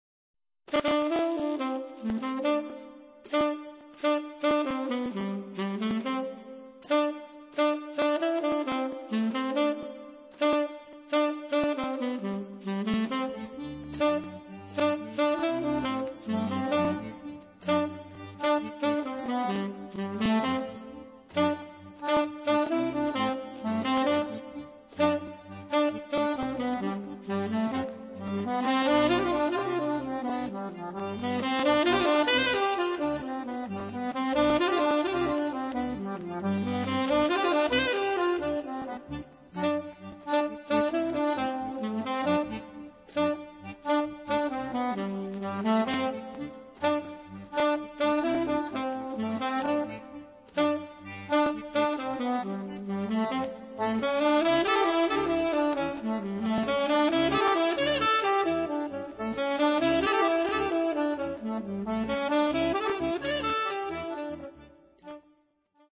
sax
fisarmonica
piano
contrabbasso